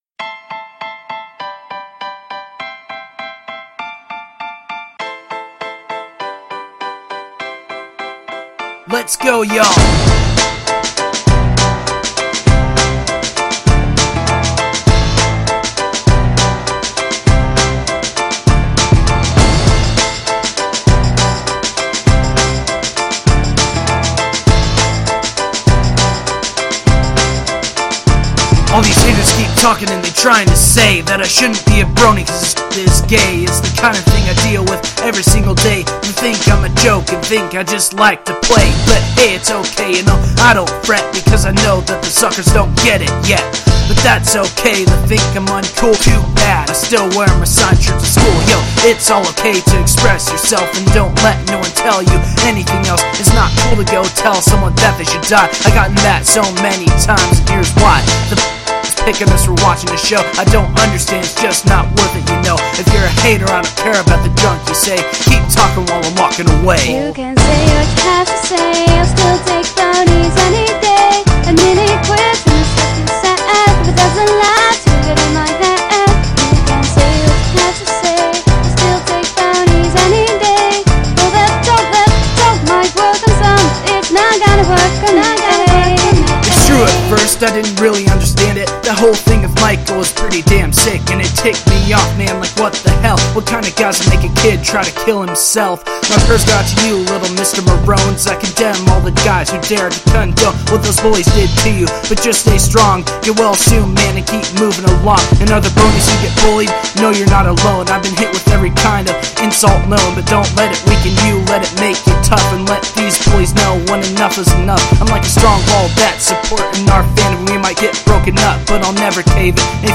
The singer is off on her notes. It seems like she was struggling a bit to keep up with the tempo of this song.
Also, some percussion variation would be nice since it's the same loop continuously...